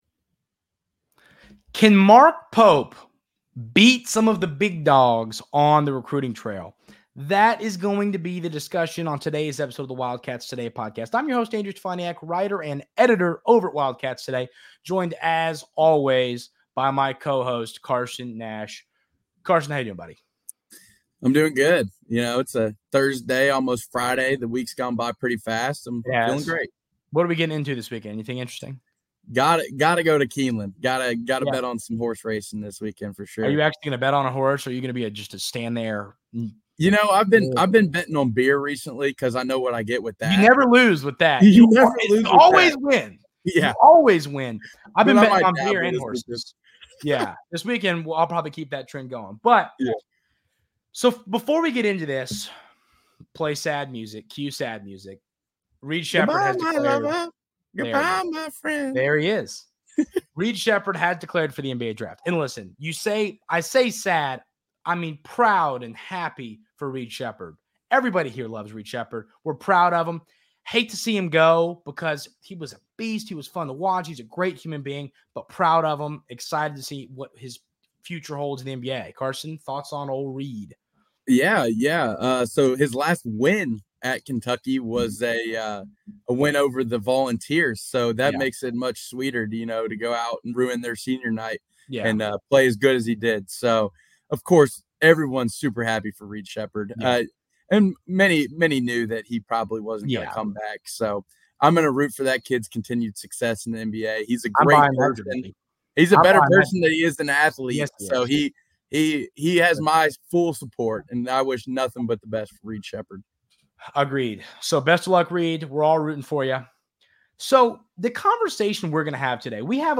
The two feel very confident that he will do an excellent job when it comes to x's and o's, but how he does recruiting will make or break the Mark Pope era in Lexington. Lastly, the two discuss some players that the coaching staff is looking at in the transfer portal.